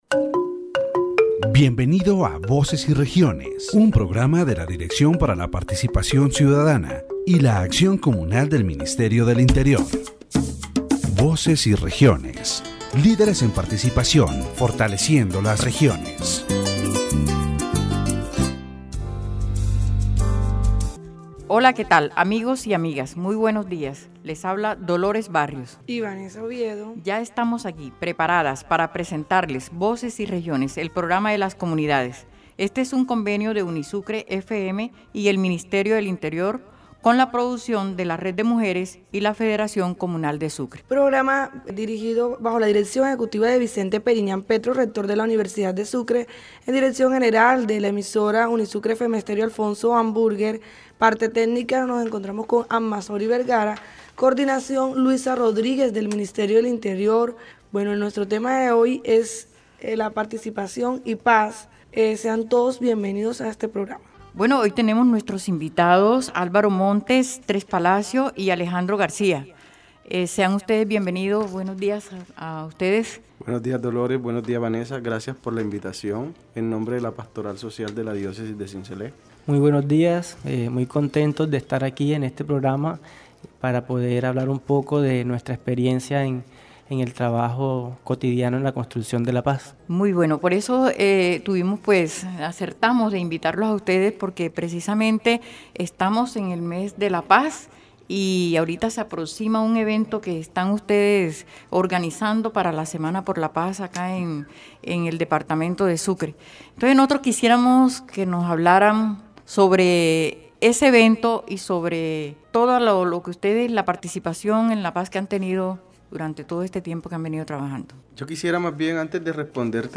The interview highlights the importance of citizen participation in peacebuilding, The guests emphasize that peace is a continuous process that requires the commitment of all social actors.